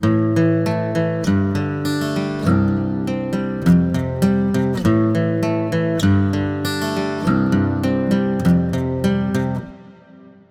AKG C414 XLII Matched Pair Stereo Set , Digilab SPM102 (���), RME FF800